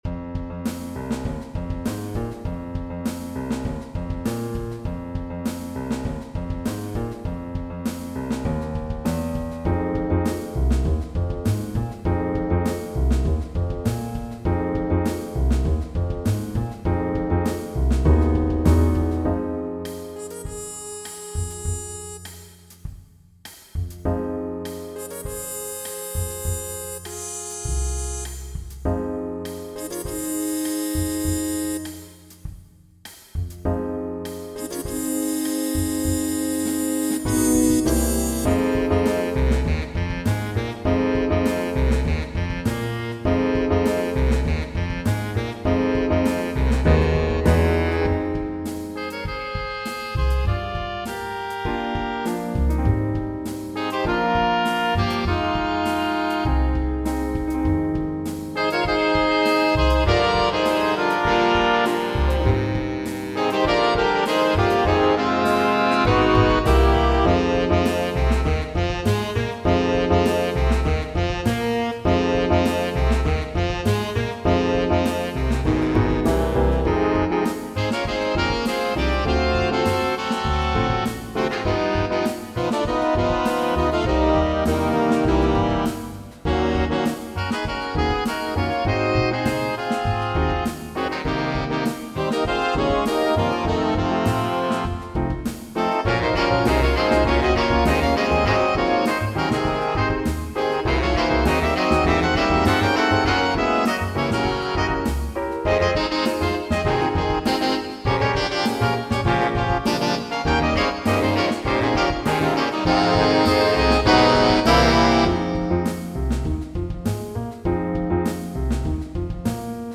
Big Band
All audio files are computer-generated.
A funk tune (tempo = 100) featuring a recurring riff with trumpet, tenor sax and trombone improvised solos. Piano requires comping.